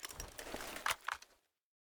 inspect_jammed.ogg